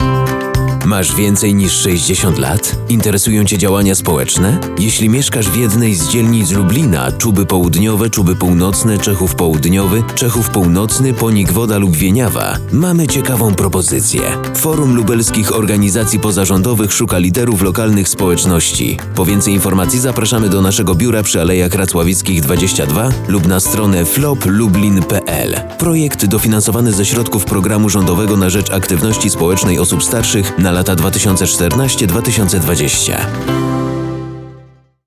Do odsłuchania spoty radiowe reklamujące kampanię społeczną „Proaktywni 60+ na START”, które zostały wyemitowane w Polskim Radiu Lublin:
Spot reklamowy 2